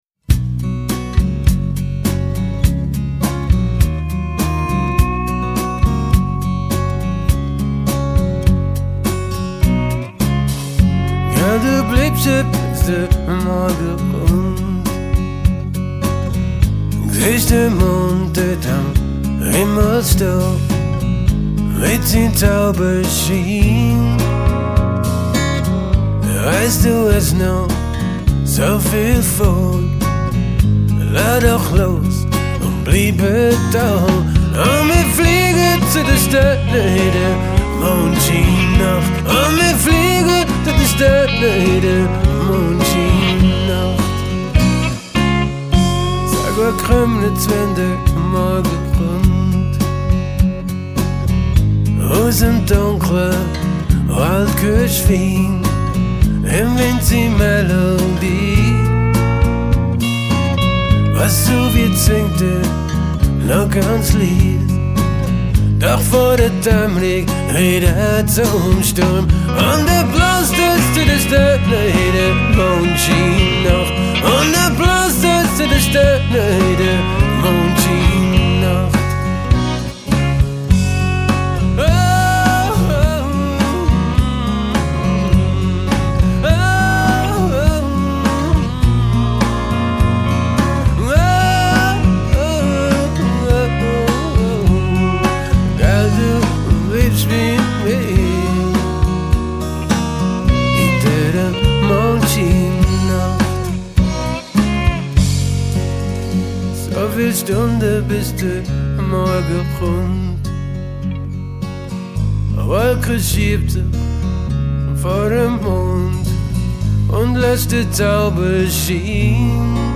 Gitarren
Schlagzeug
Bass
Gesang